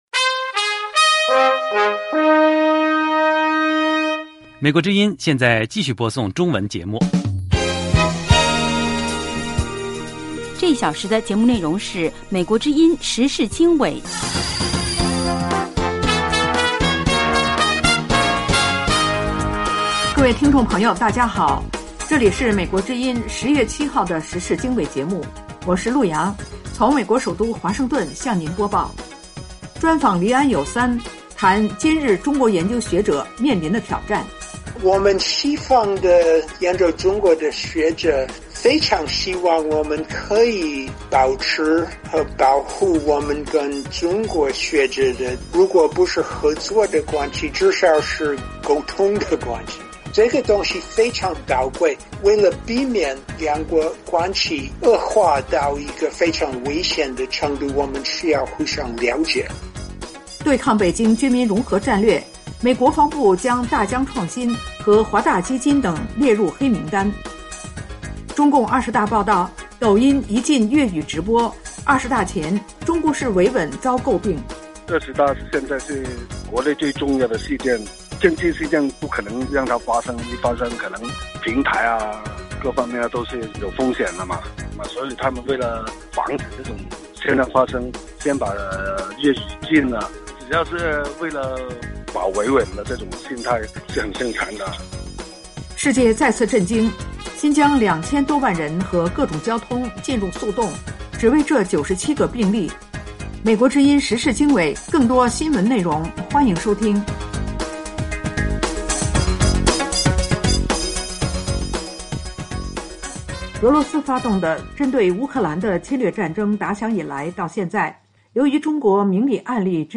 时事经纬(2022年10月7日)：1/专访黎安友（3）：谈今日中国研究学者面临挑战。2/对抗北京“军民融合”战略，美国防部将“大疆创新”和“华大基因”等列入黑名单。